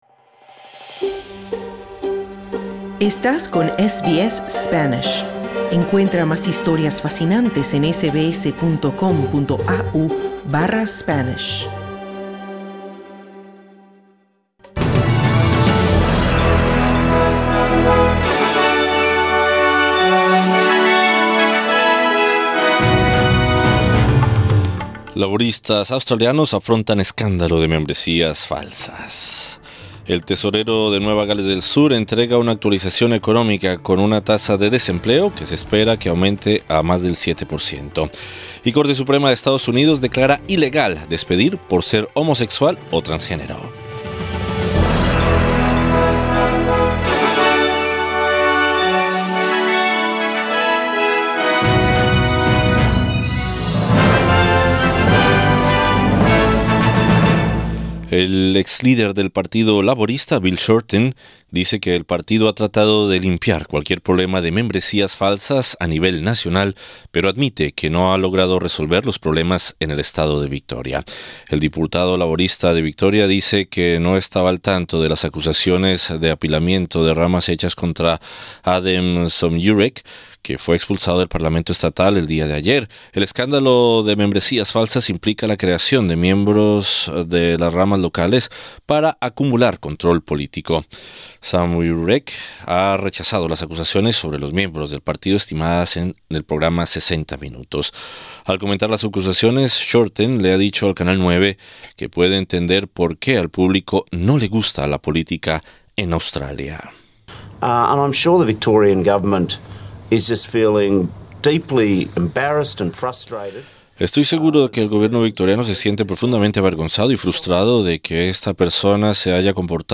Noticias SBS Spanish | 16 de junio 2020